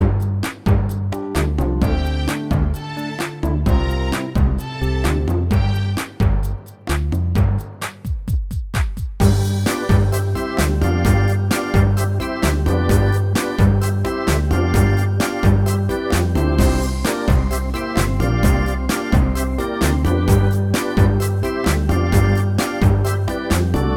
No Guitars Pop (2000s) 3:42 Buy £1.50